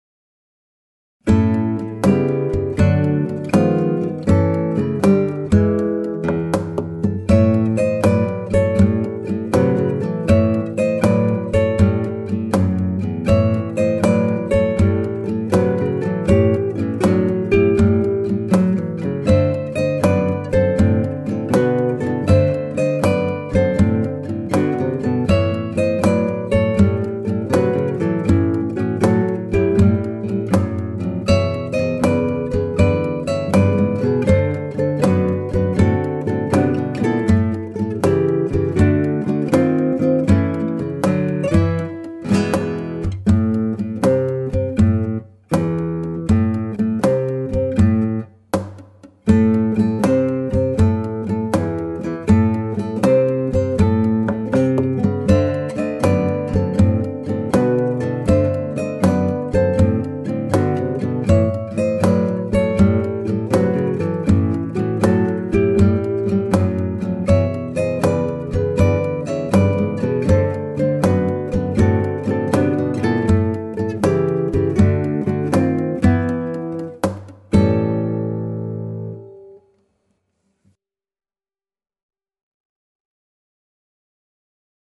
Guitar Percussion & Groove Ensemble mit CD/CD-ROM